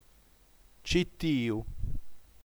ci.tì.u (f. -tì.a/-tì.da) – [tʃi’t:iu] (f. [-‘t:ia/-‘t:iða])